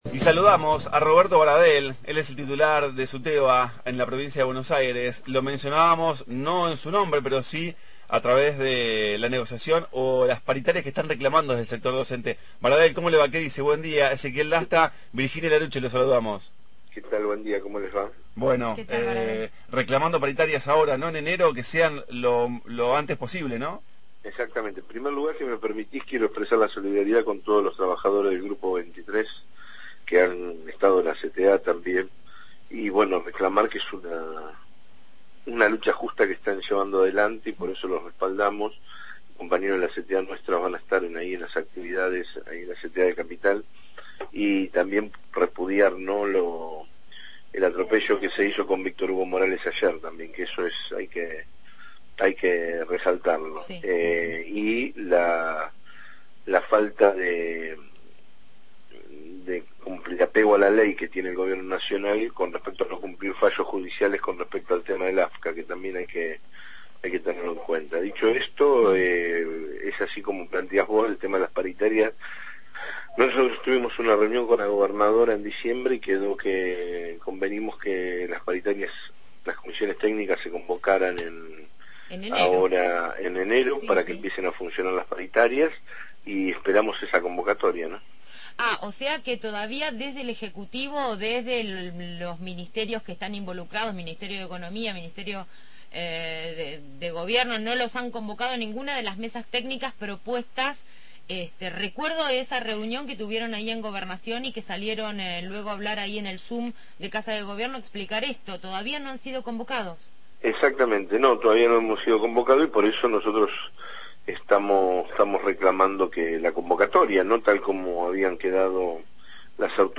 Entrevista a Roberto Baradel, titular de SUTEBA, sobre paritarias docentes